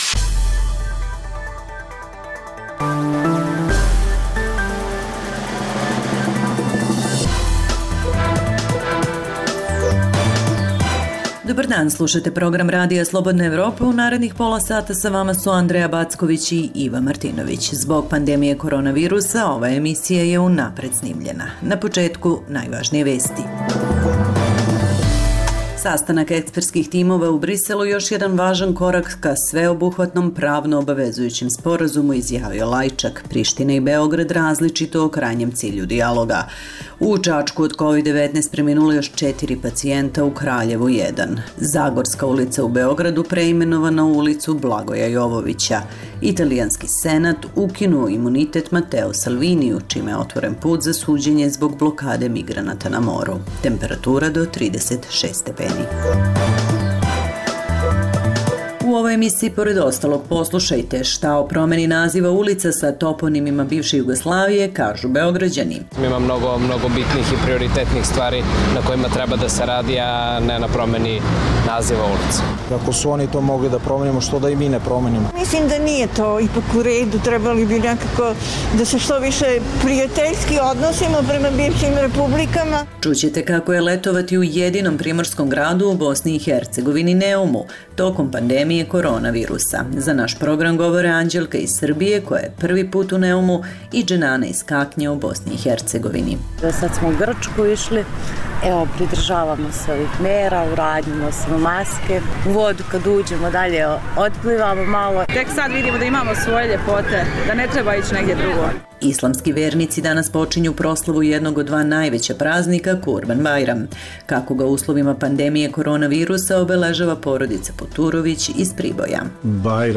Zbog pandemije korona virusa, ova je emisija unapred snimljena. Sastanak ekspertskih timova u Briselu još jedan važan korak ka sveobuhvatnom pravno obavezujućem sporazumu, izjavio Lajčak. Zagorska ulica u Beogradu preimenovana u ulicu Blagoja Jovovića.